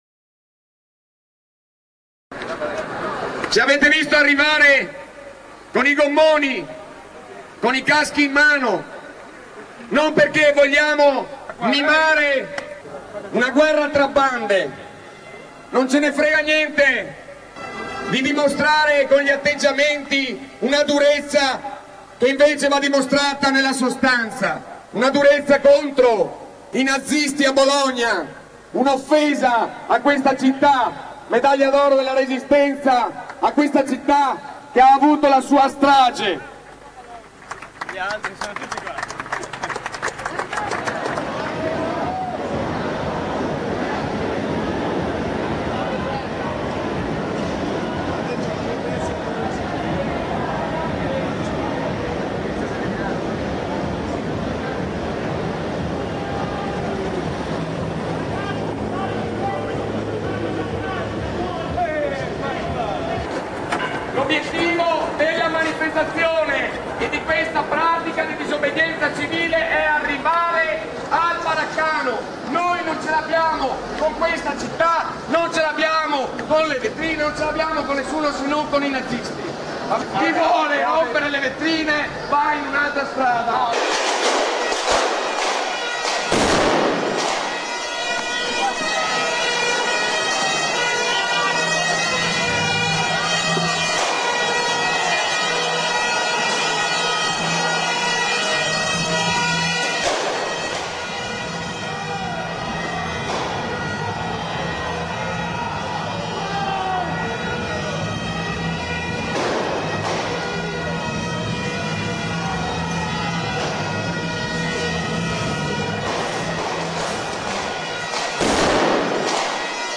Ore 15.00 La polizia carica il corteo
riot2.rm